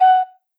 noteblock_flute.wav